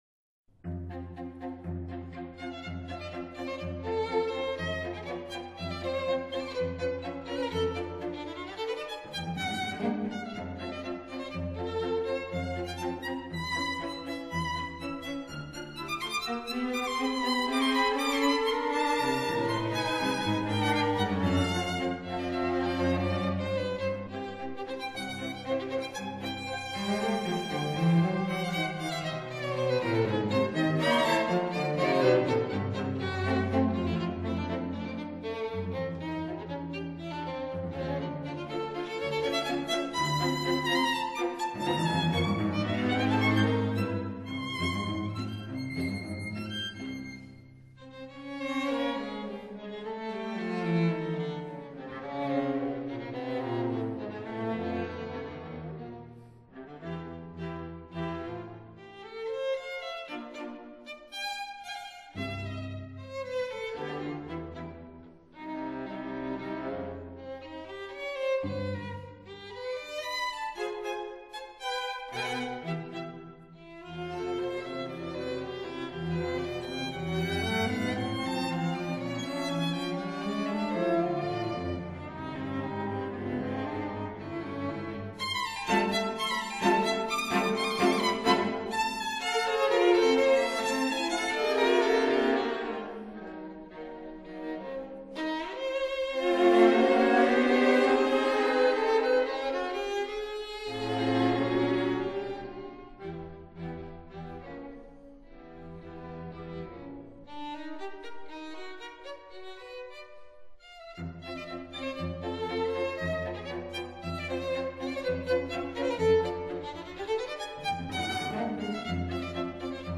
分辑：CD 23-27  弦乐四重奏全集